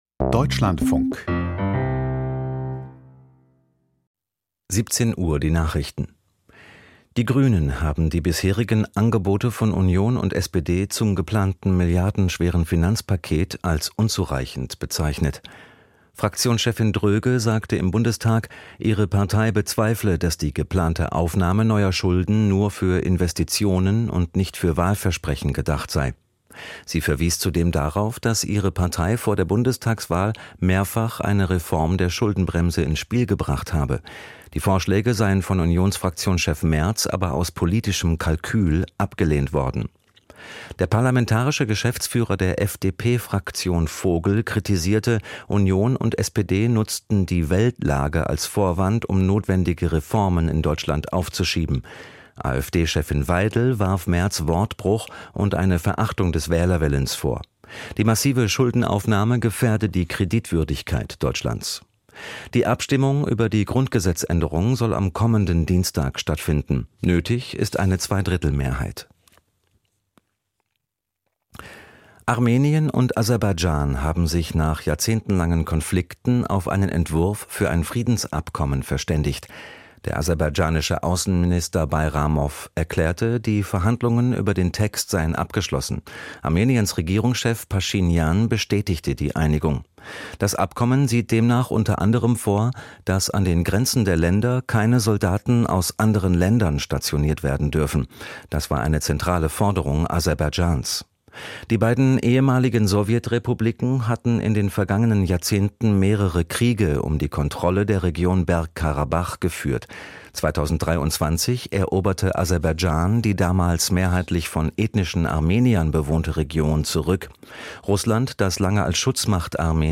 Die Deutschlandfunk-Nachrichten vom 13.03.2025, 17:00 Uhr